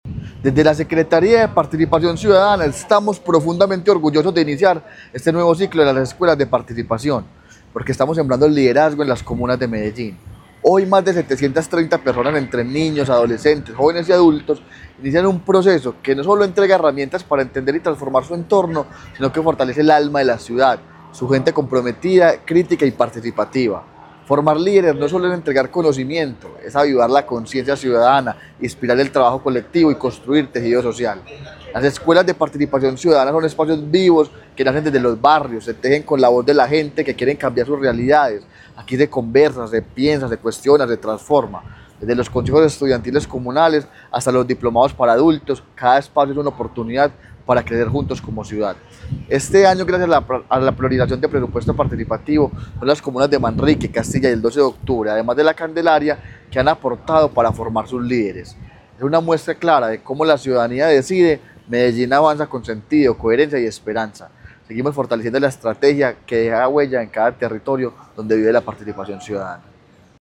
Declaraciones del secretario de Participación Ciudadana, Camilo Cano Montoya
Declaraciones-del-secretario-de-Participacion-Ciudadana-Camilo-Cano-Montoya.mp3